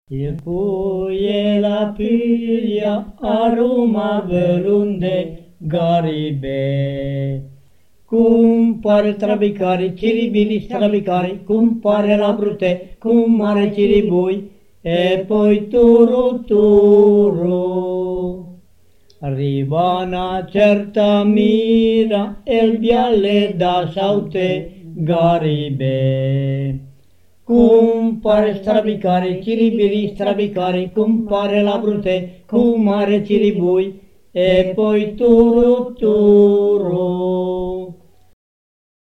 Pidocchio e pulce / [registrata a Garzigliana (TO), nel 1981